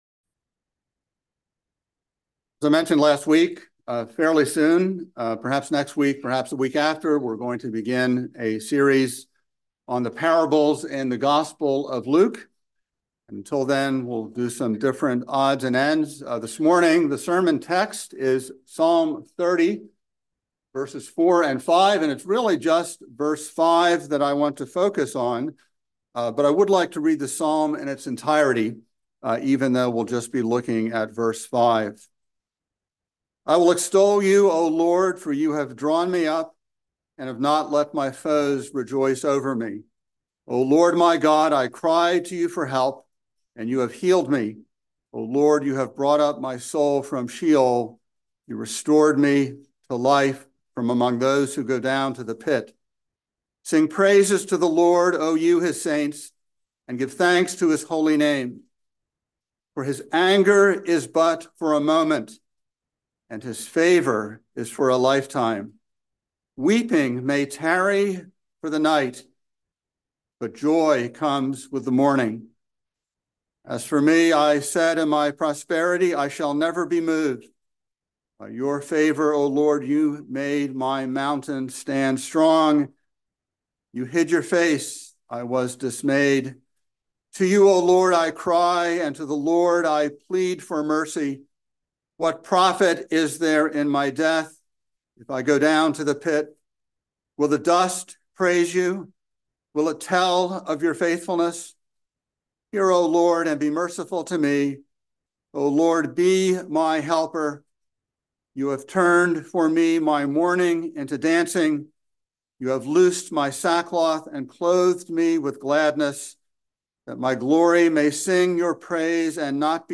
by Trinity Presbyterian Church | Jan 22, 2024 | Sermon